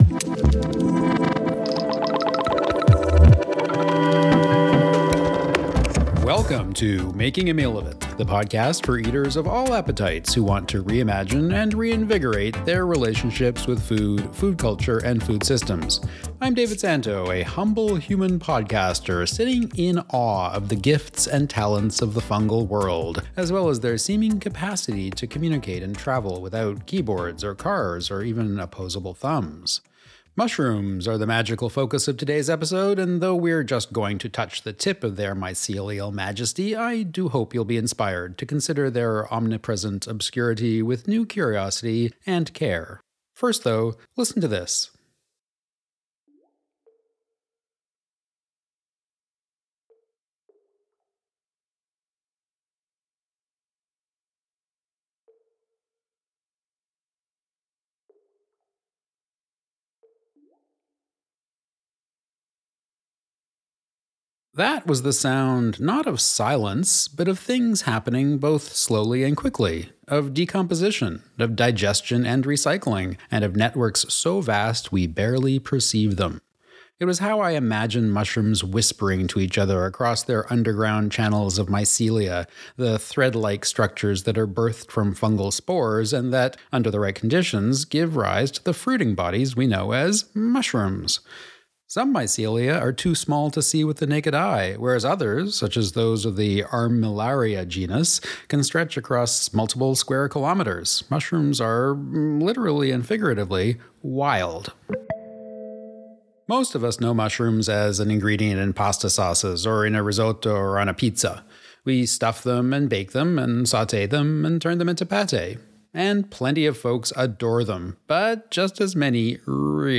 Mushrooms are the magical focus of this episode, though we only just touch the tip of their mycelial majesty. Conversations with entrepreneurs